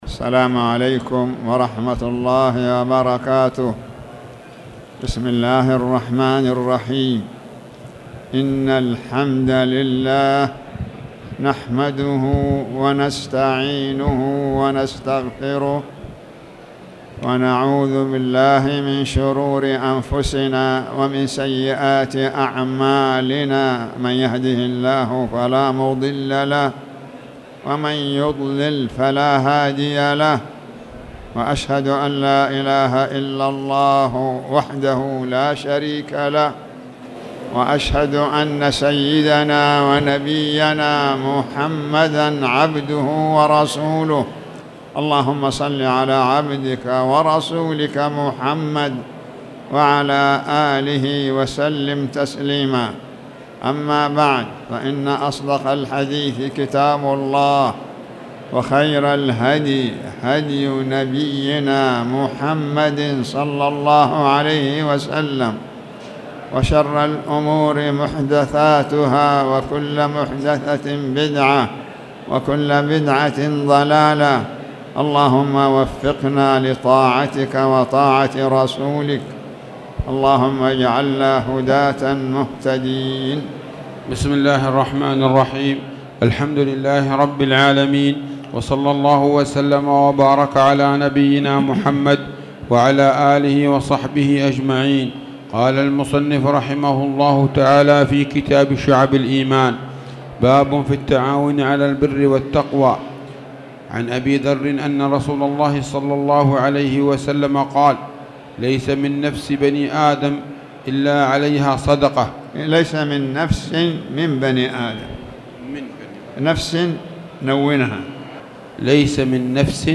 تاريخ النشر ٢٧ شعبان ١٤٣٨ هـ المكان: المسجد الحرام الشيخ